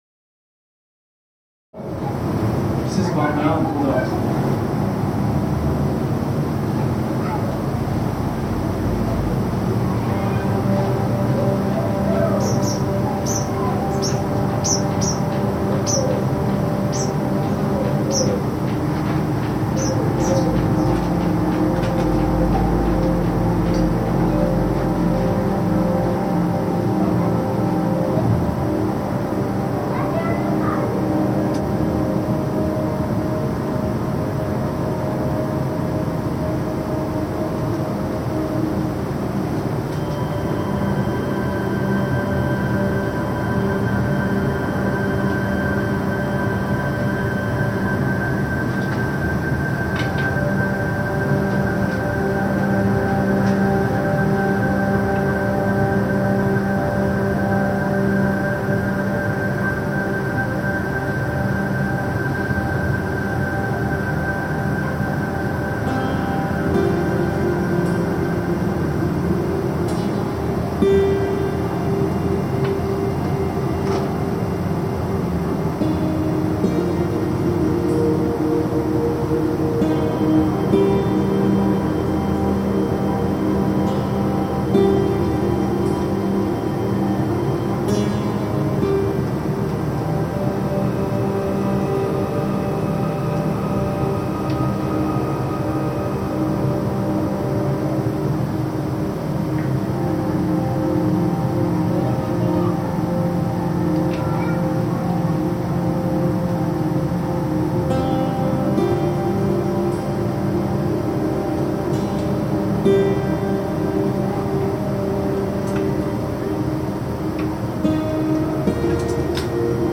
Soundscape from Shah Mama, Bamiyan Valley